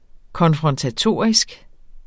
Udtale [ kʌnfʁʌntaˈtoˀɐ̯isg ]